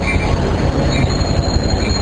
描述：train passing over bridge in montreal (different again)
标签： passing train fieldrecording